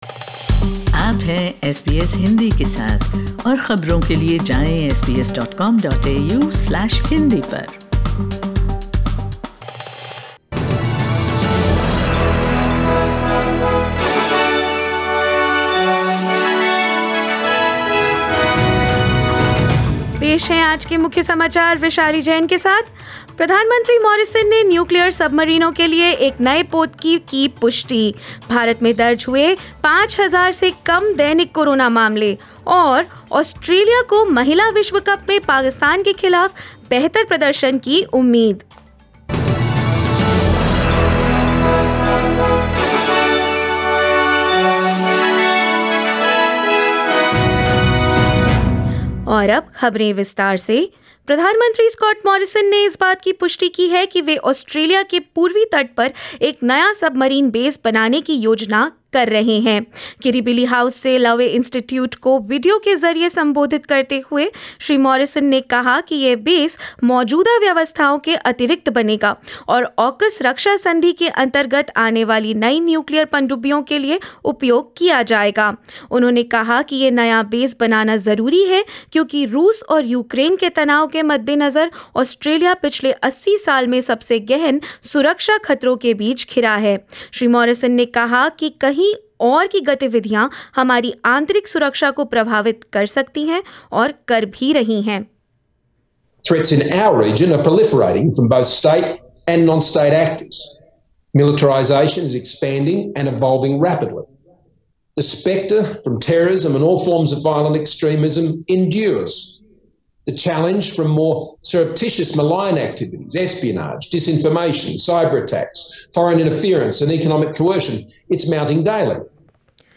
In this latest SBS Hindi bulletin: Australia's Prime Minister has confirmed plans for a submarine base on the east coast; India registers less than 5,000 daily COVID-19 cases; Australia hopes to perform better against Pakistan in Women's Cricket World Cup and more news.